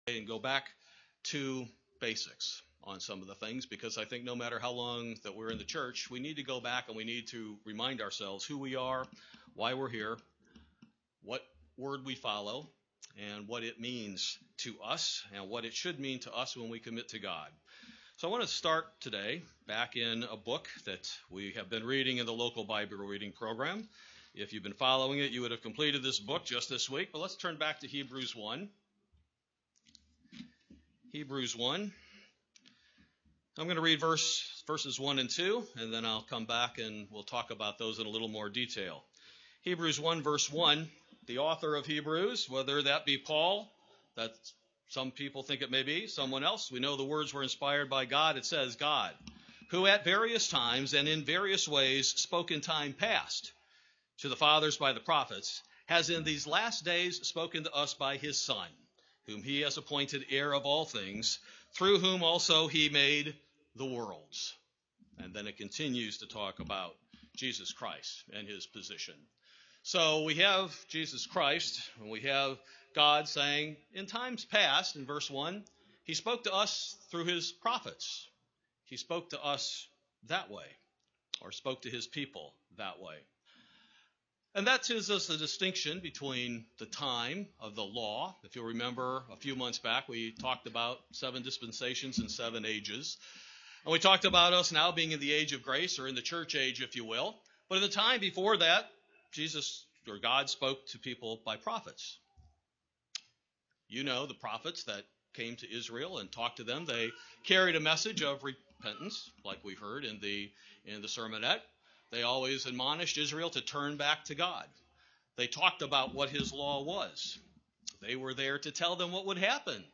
UCG Sermon the Word belief Transcript This transcript was generated by AI and may contain errors.